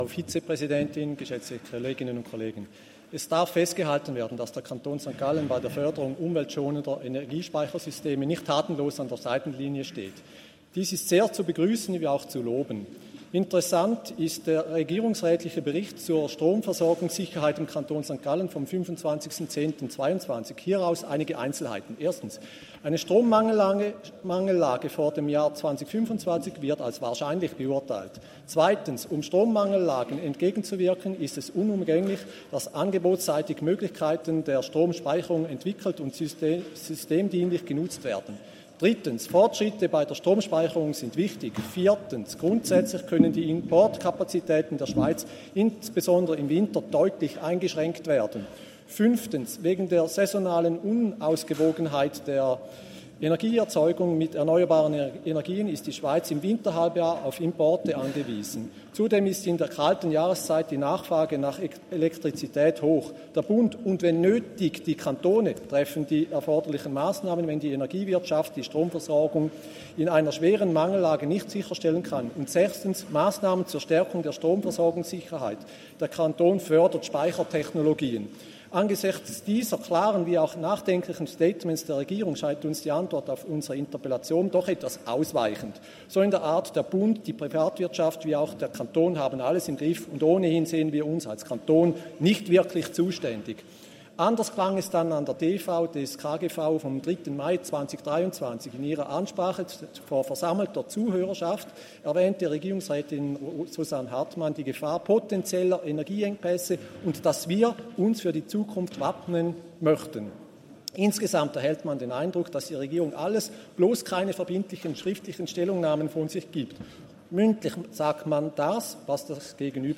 20.9.2023Wortmeldung
Session des Kantonsrates vom 18. bis 20. September 2023, Herbstsession